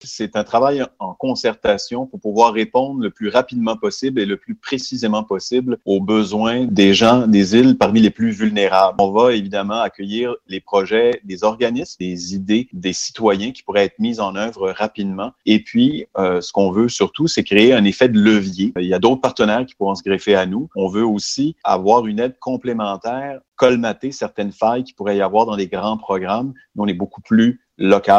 Le député, Joël Arseneau, souligne qu’avec l’annonce d’un rehaussement de l’enveloppe discrétionnaire des élus provinciaux, il tenait à mettre en place un processus de concertation pour pallier aux contrecoups de la crise actuelle.